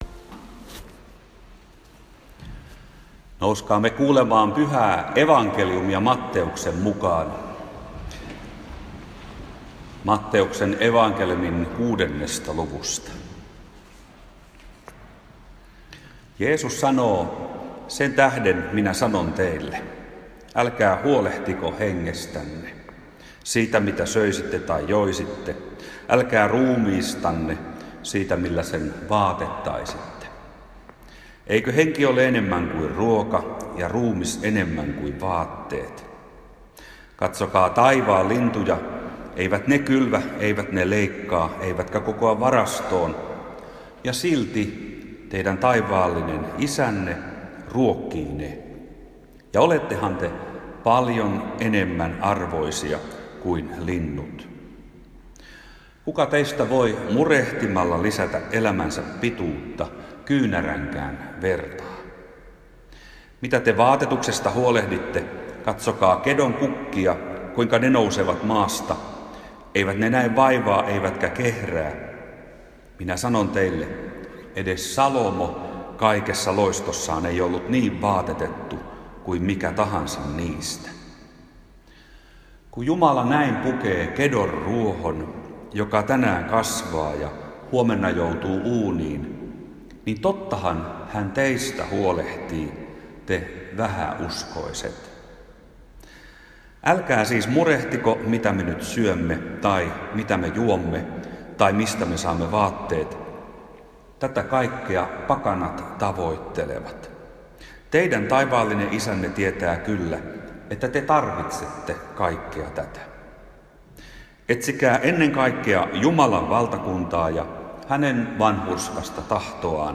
saarna taivaan linnuista, kedon kukista ja ihmissydämestä Isonkyrön kirkossa Kyrönmaan evankeliumijuhlassa 4.9.2016.